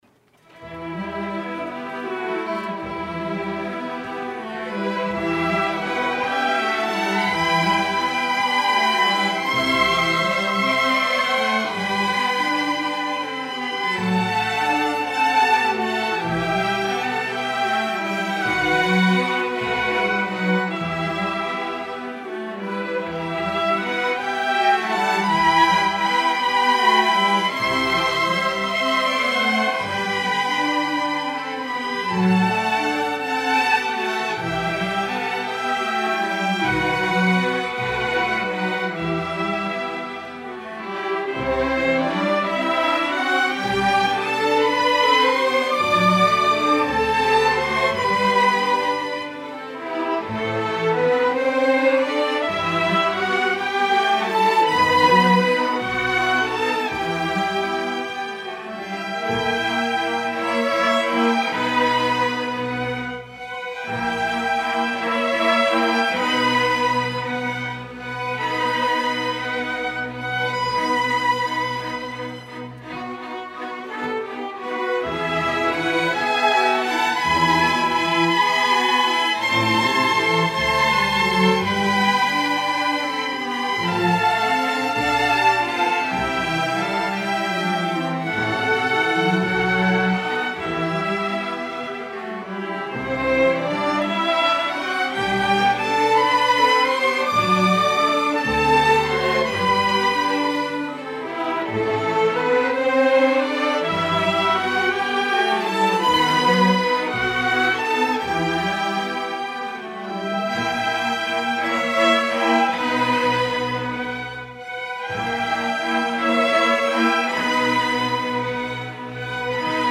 Queen City Community Orchestra
Fall 2014 Concert